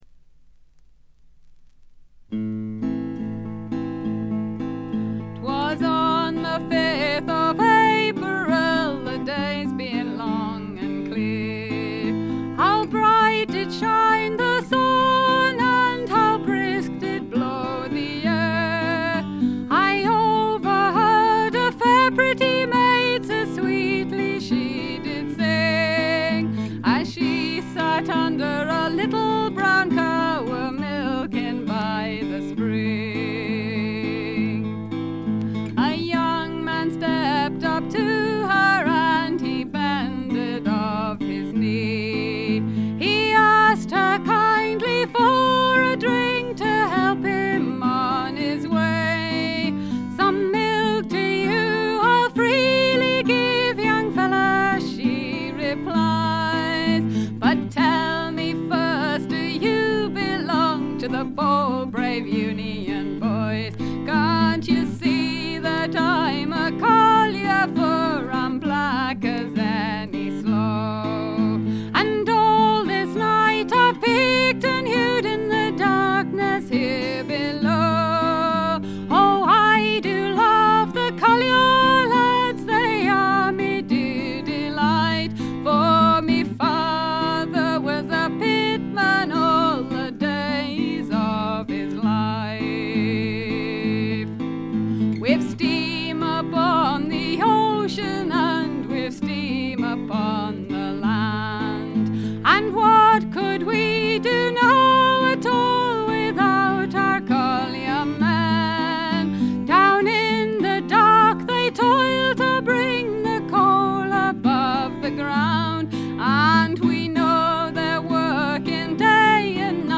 本作でも無伴奏もしくは最小限のバッキングを従えての歌唱は、尋常ではない美しさと強靭さを兼ね備えています。
試聴曲は現品からの取り込み音源です。
acc. guitar
vocals
guitar